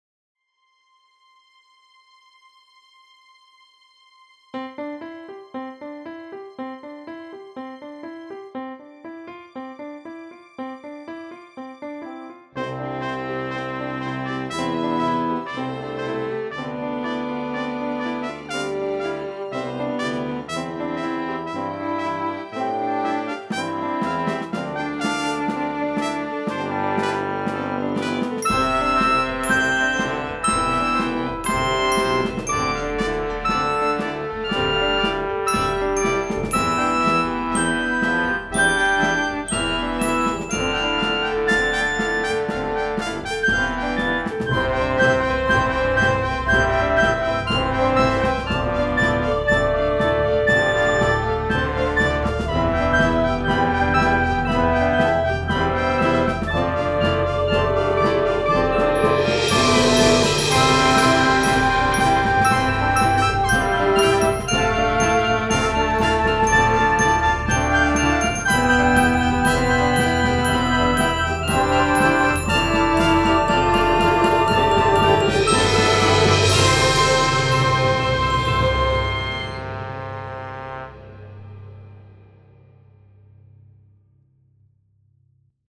newbie orchestral big build mix - how much reverb is enough for tv?